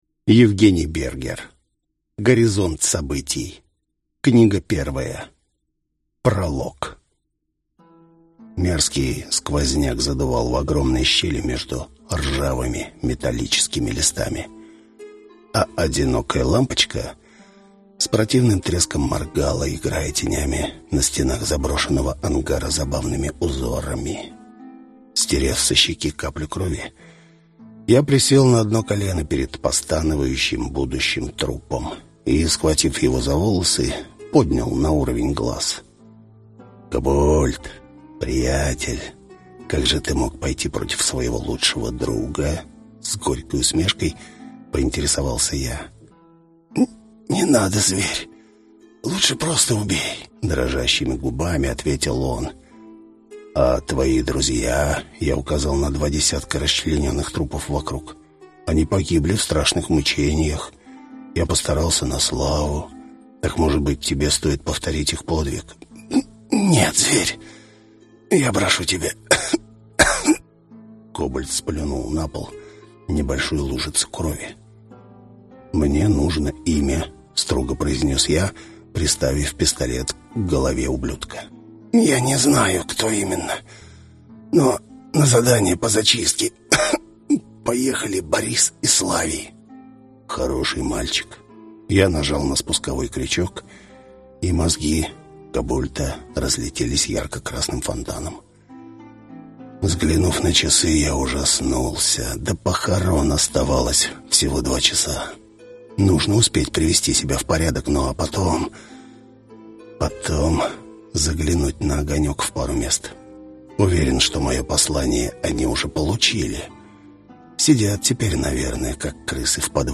Аудиокнига Горизонт событий. Книга 1 | Библиотека аудиокниг